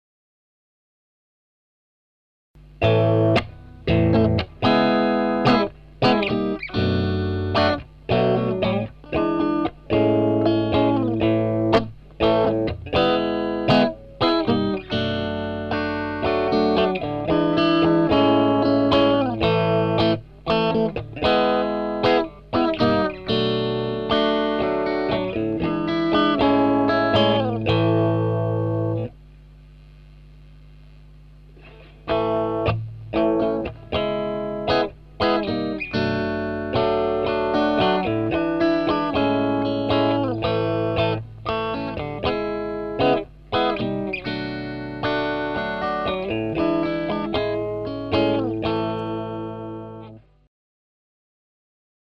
heres my first analogue recording with a mexi strat into a solid state combo to kent high impedance mic to Akai ds4000 mkii to interface converted to mp3. No effects no mastering just raw.